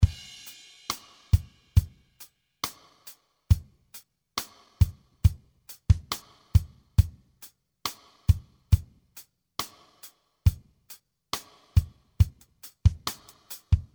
69 bpm slow and romantic rhythm.
This loop track contains 21 loops.